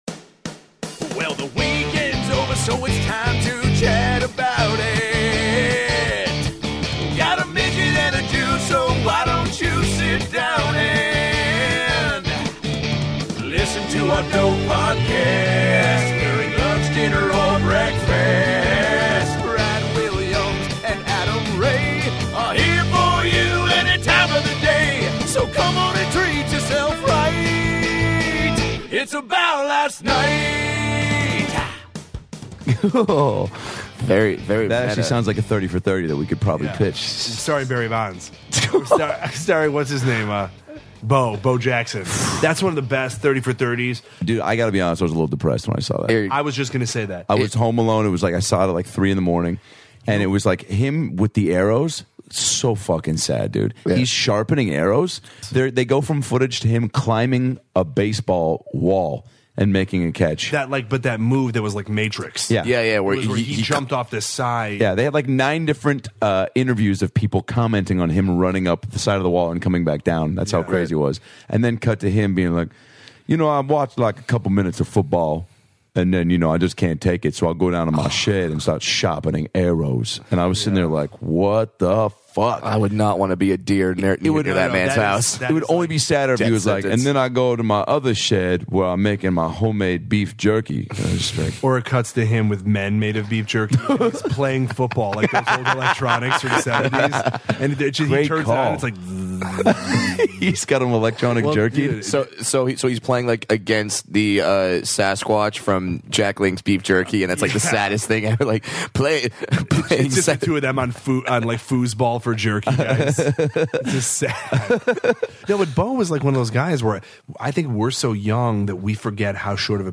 TWO BROKE GIRLS star Jonathan Kite returns to talk about his love for the NBA, does some amazing impressions, and prank calls a Thai restaurant as Jeff Bridges.